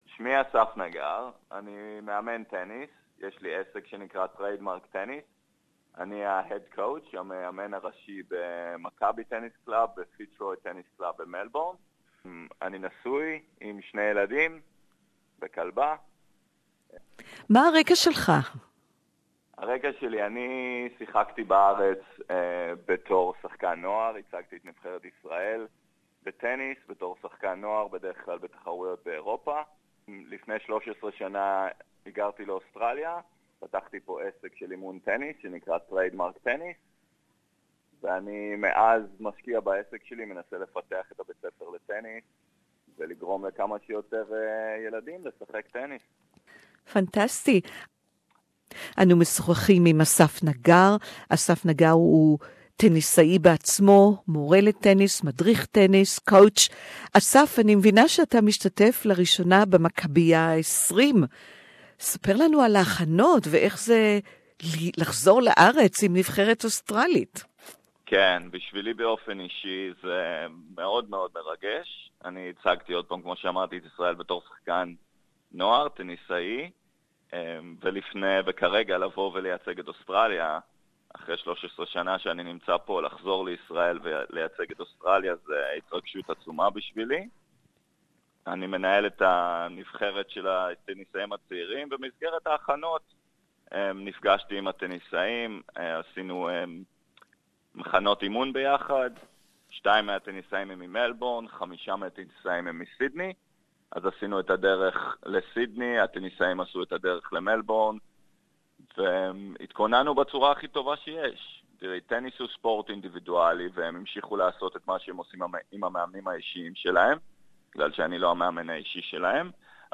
Hebrew Interview